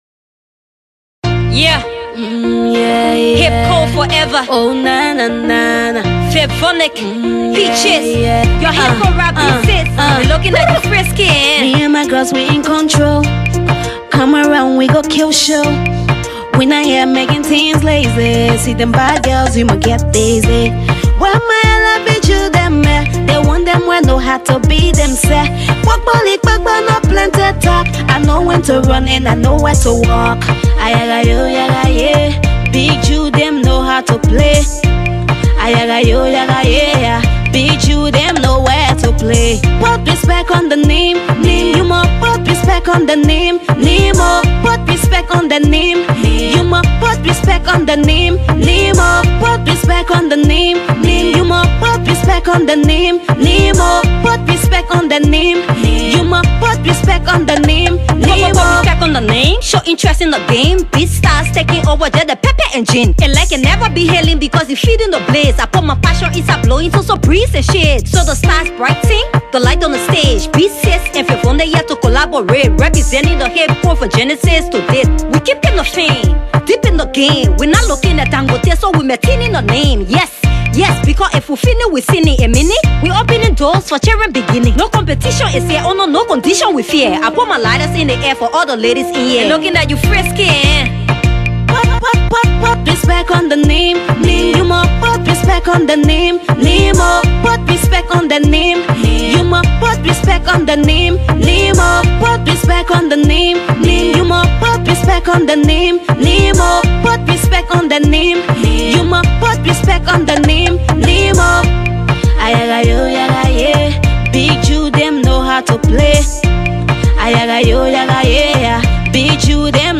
/ Dancehall, Hip-Co / By
dancehall tune
Hip-co rapper